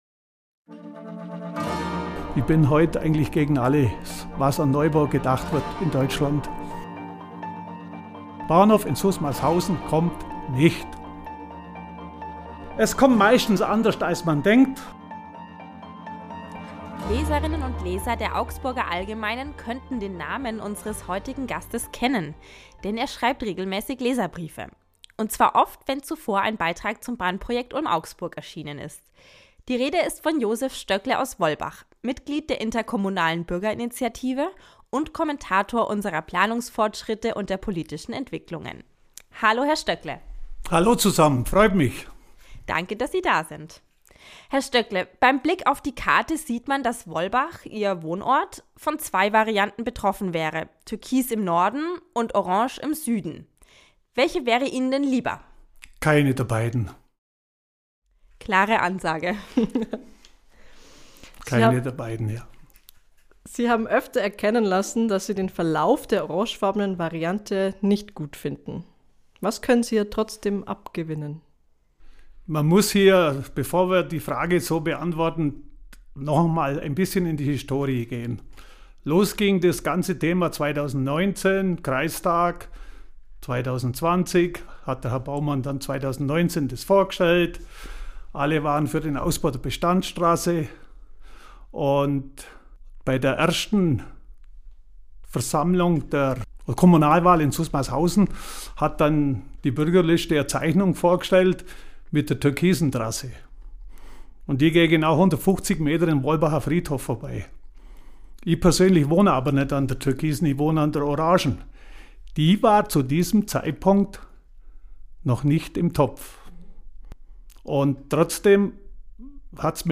Ein Gespräch über Vertrauen in den technologischen Fortschritt, sein Verständnis von Rebellion und was denn nun richtig und was falsch ist.